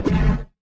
Robot Whoosh Notification.wav